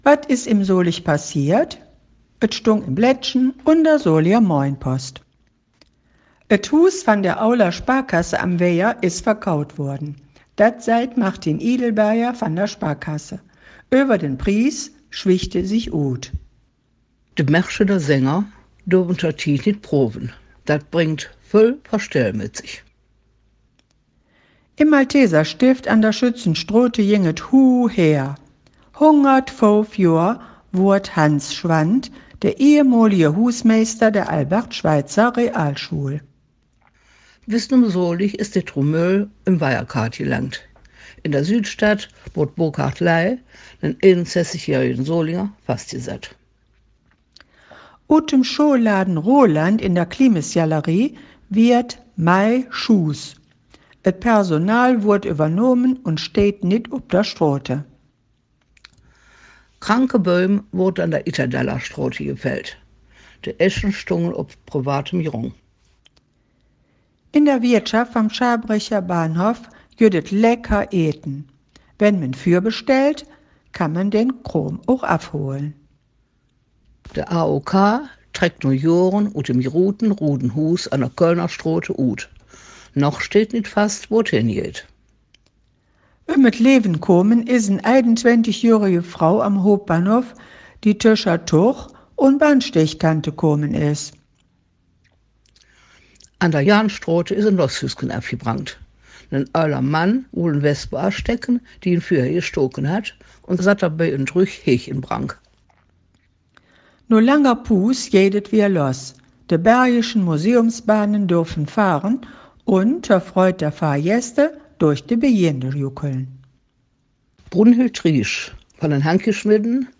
Nachrichten in Solinger Platt – aktuelle Mundart
Solinger-Platt-News-20kw31.mp3